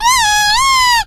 squeak_die_vo_01.ogg